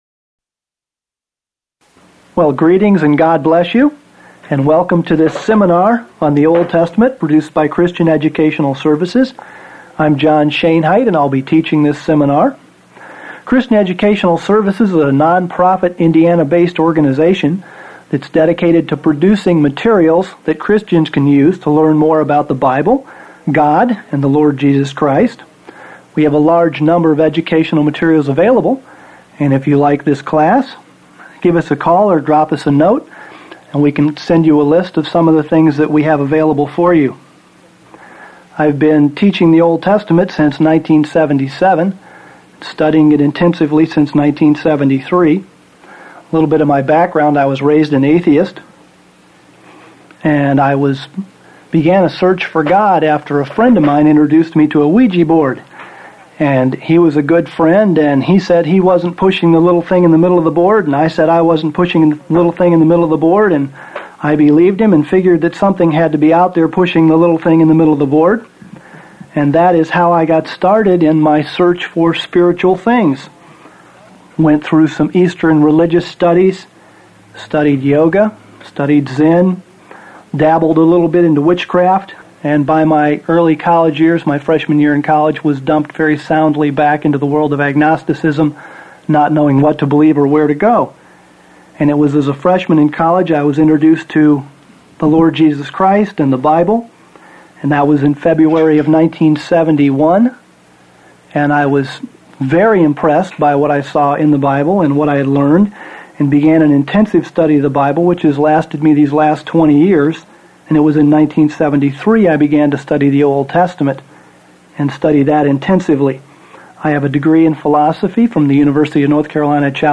This introductory session of the seminar covers two foundational topics. It explains the reasons why we should study the Old Testament, and it presents a detailed overview of the Old Testament.